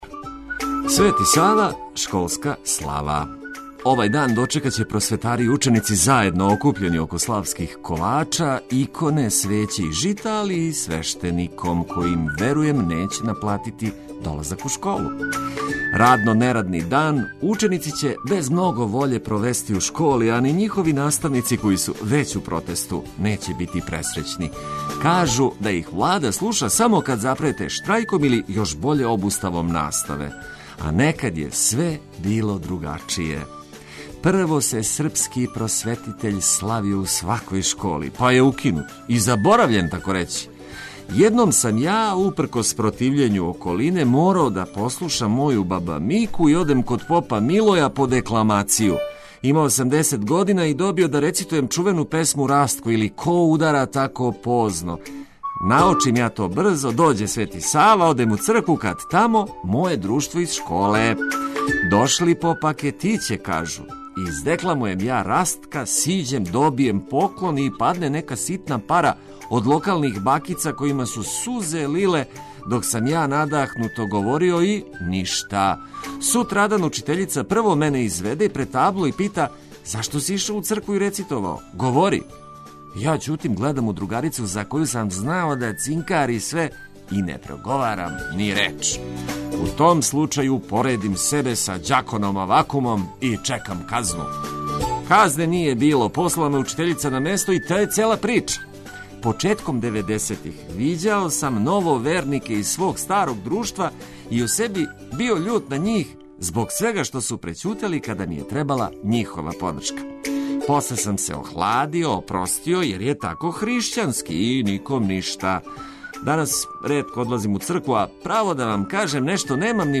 Уз честитке ђацима и просветарима будићемо вас преносећи вам актуелне информације и заједно слушати најлепшу музику за буђење.